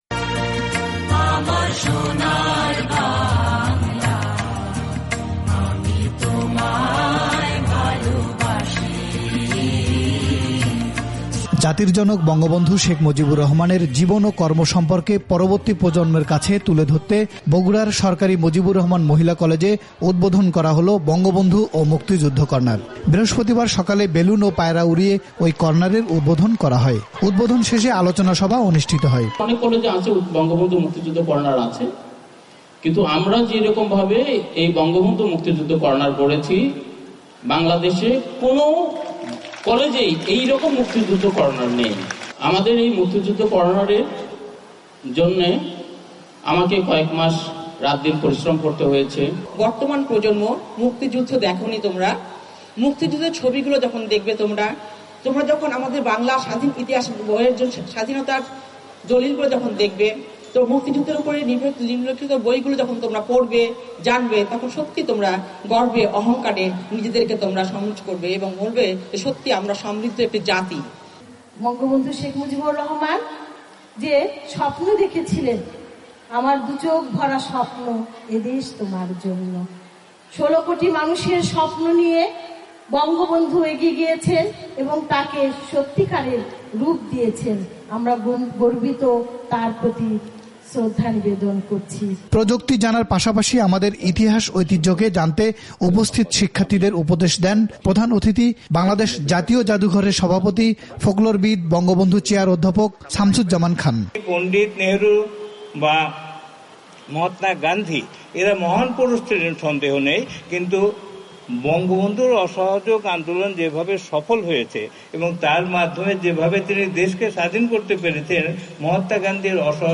বিস্তারিত জানাচ্ছেন আমাদের বগুড়া সংবাদদাতা